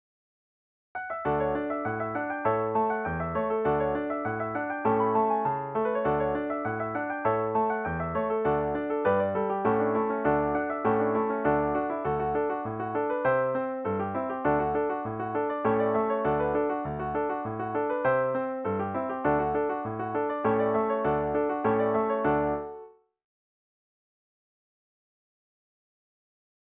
DIGITAL SHEET MUSIC - PIANO ACCORDION SOLO